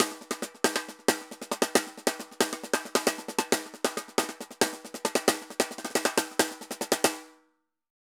Tambor_Merengue 136-1.wav